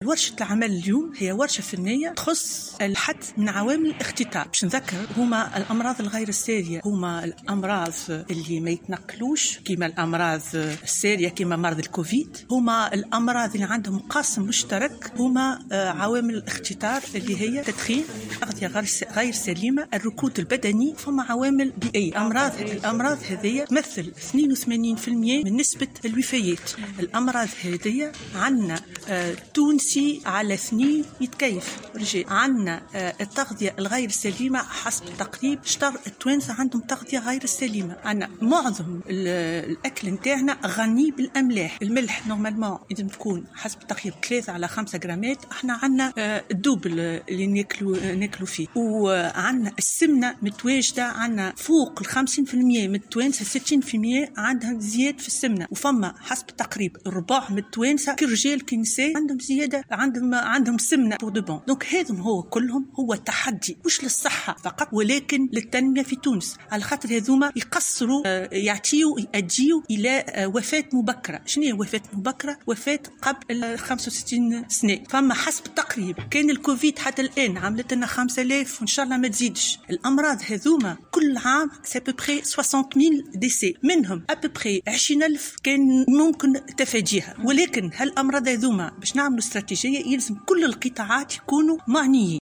كما أوضحت في تصريح للجوهرة أف-أم، على هامش ورشة عمل فنية لإعداد مخطط عملي للإستراتيجية الوطنية متعددة القطاعات للوقاية والحد من الأمراض غير السارية بالتعاون مع منظمة الصحة العالمية، أن الأمراض غير السارية تمثل 82 % من نسبة الوفيات في تونس وأن نصف التونسيين مدخنين. وأكدت المتحدثة أن نصف التونسيين تغذيتهم غير سليمة و 60% منهن يعانون من السمنة التي تؤدي الى الوفاة المبكر.